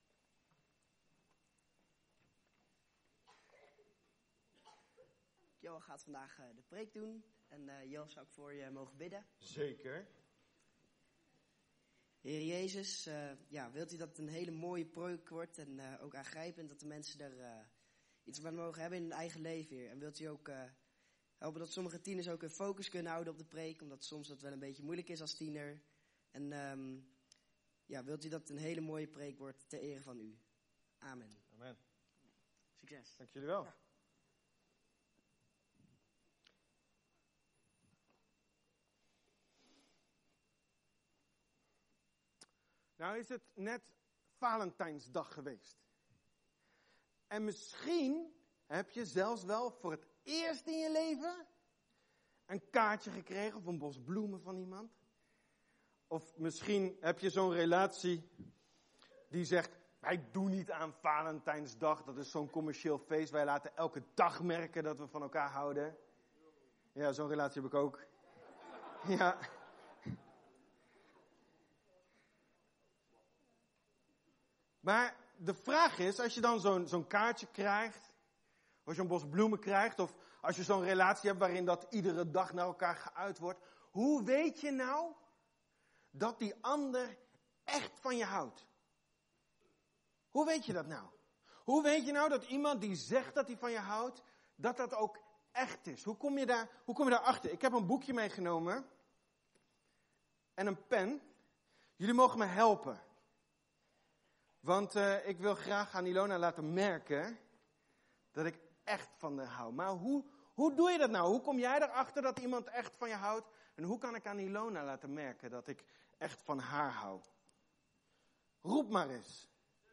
We komen elke zondagmorgen bij elkaar om God te aanbidden.